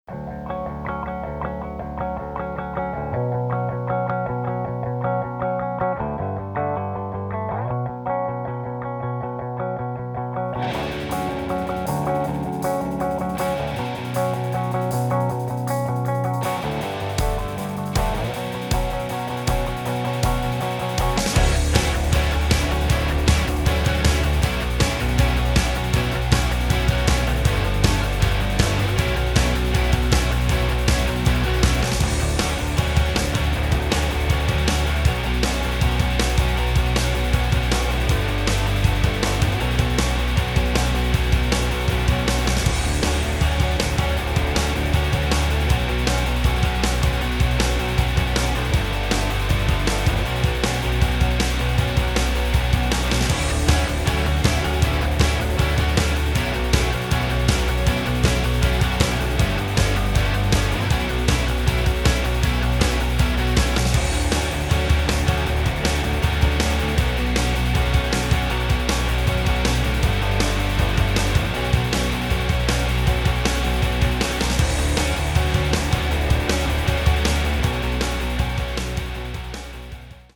• Качество: 320, Stereo
гитара
мощные
грустные
без слов
инструментальные
Alternative Rock
Hard rock
post-grunge
рок